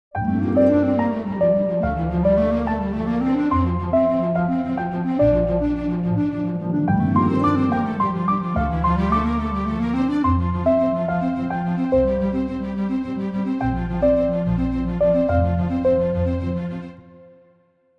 3. Renforcement du leitmotiv (par doublures) :
Je double le phrasé de la clarinette par les alti, puis en deuxième partie par les violons 1 (une octave au dessus, pour donner du relief).
En doublant cet instrument par des cordes, j’ai plus de profondeur et mon contrechant devient plus audible dans le mix.
avec_cordes.mp3